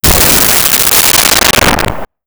Pistol Shot
Pistol Shot.wav